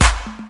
TM-88 Clap #04.wav